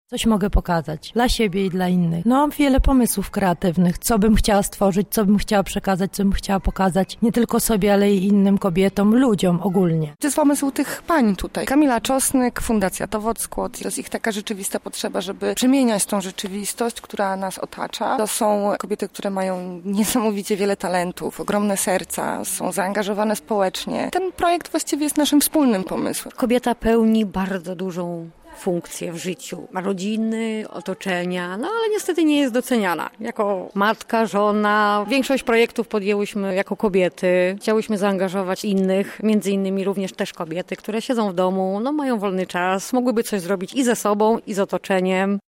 Z uczestniczkami spotkania rozmawiała nasza reporterka.